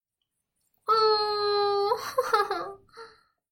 六指长笛乐曲第1号
Tag: 80 bpm Acoustic Loops Woodwind Loops 3.13 MB wav Key : Unknown